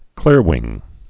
(klîrwĭng)